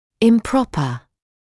[ɪm’prɔpə][им’пропэ]неправильный, ошибочный; неподходящий, неуместный